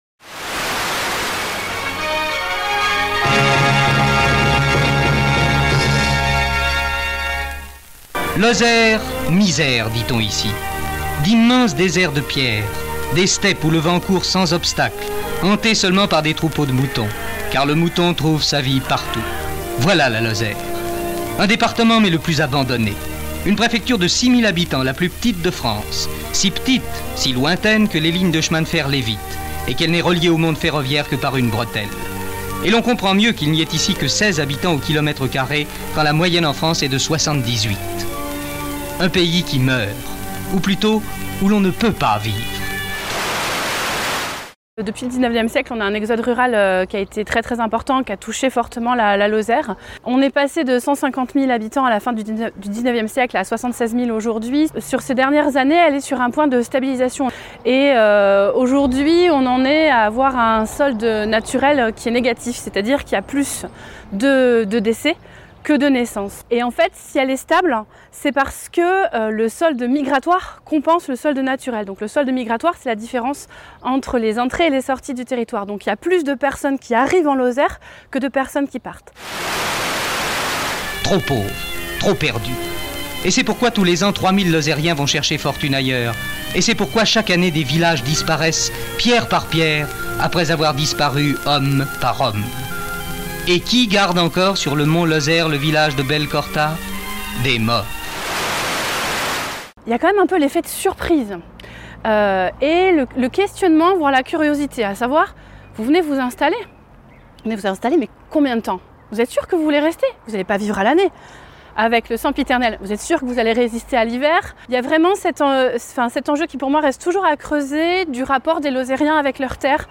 Un spectacle théâtral & musical
le 8 mars dernier au théâtre Register, 48FM s’est rendue sur place pour enregistrer la pièce.